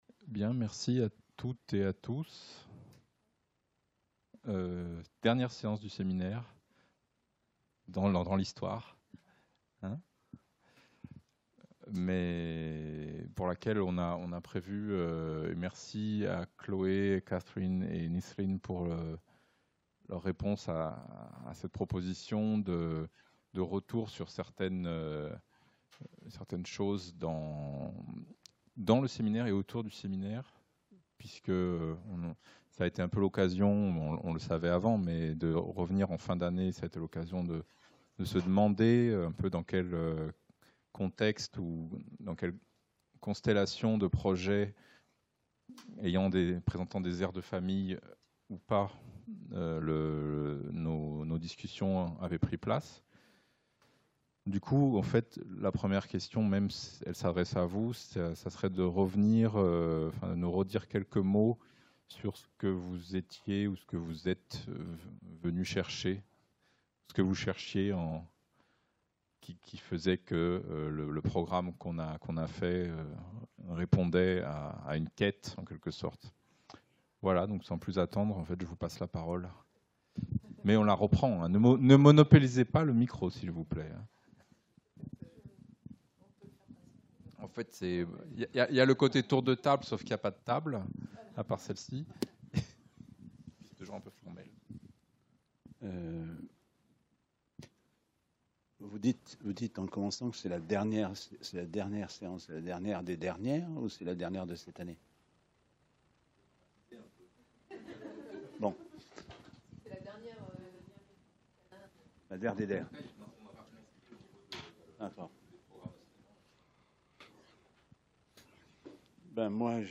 Séance de clôture